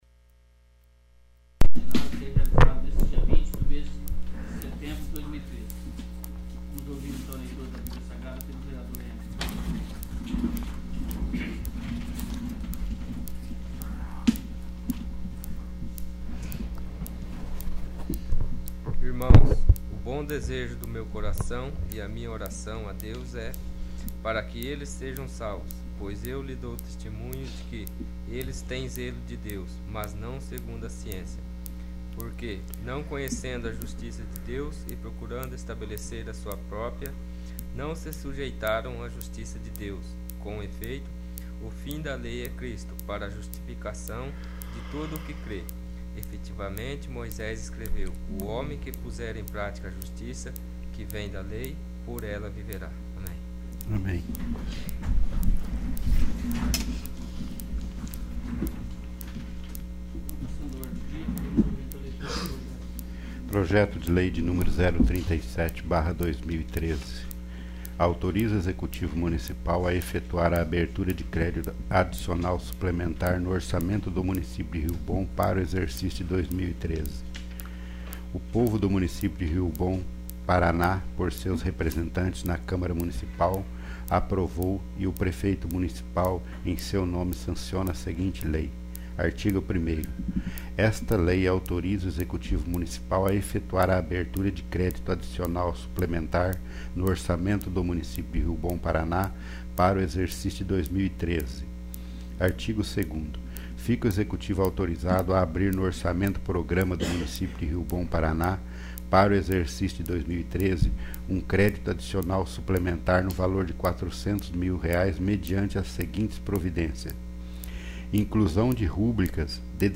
27º. Sessão Extraordinária